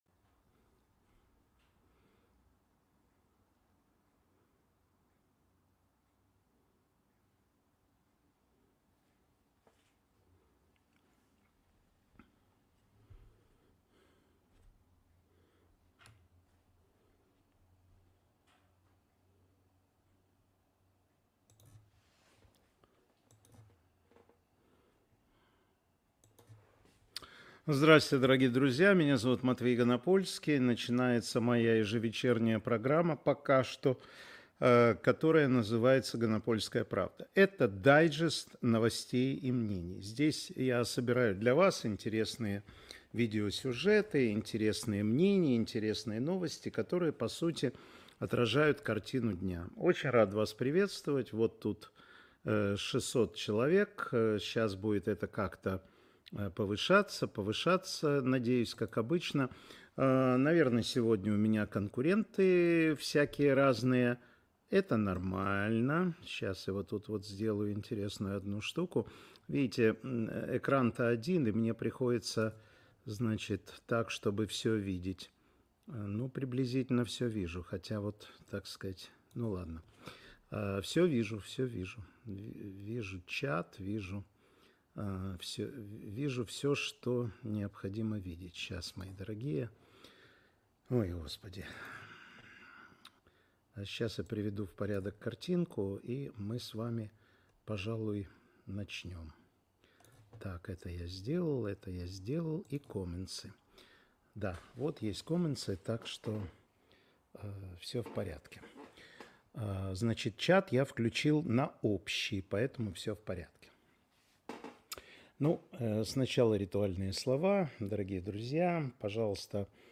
Информационно-аналитическая программа Матвея Ганапольского